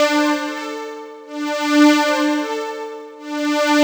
cch_synth_loop_sfur_125_Dm.wav